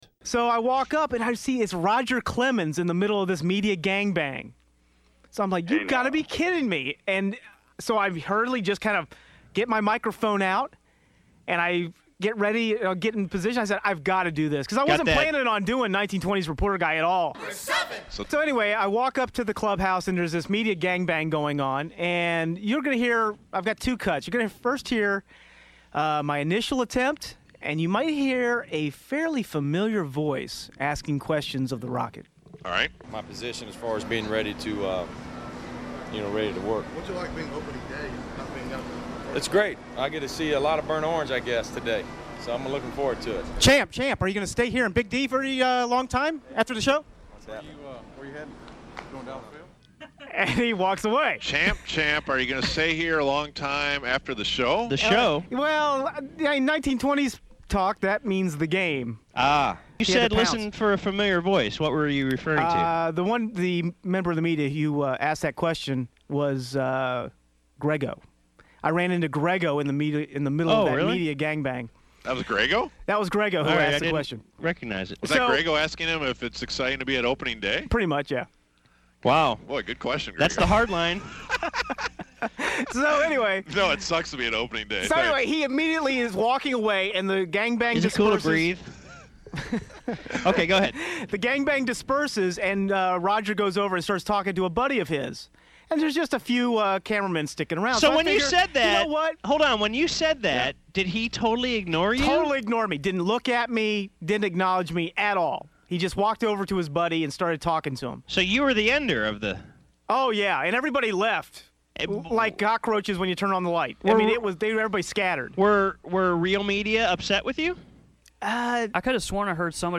You also get to hear a familiar voice asking a question at the beginning.